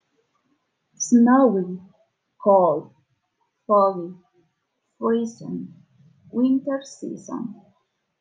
Flashcards con palabras e imágenes sobre las estaciones y palabras clave de elementos asociados del clima en ingles, puedes escuchar la pronunciación haciendo clic en el botón play.